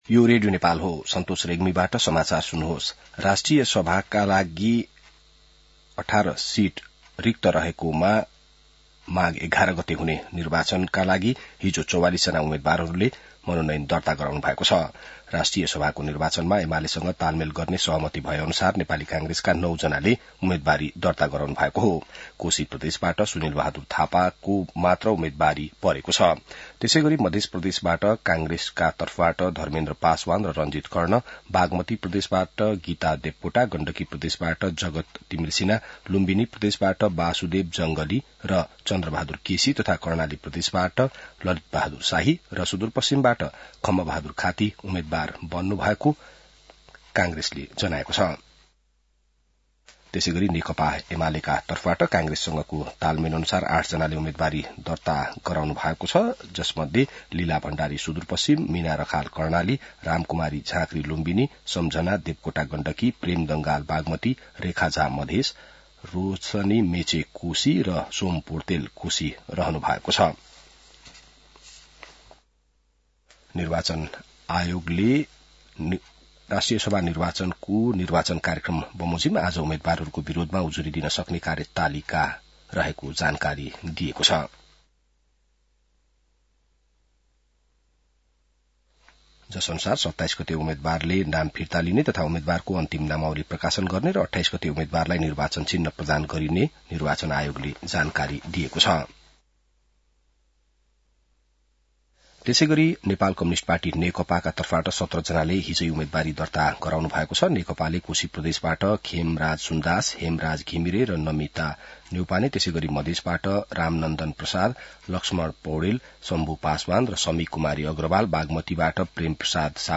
बिहान ६ बजेको नेपाली समाचार : २४ पुष , २०८२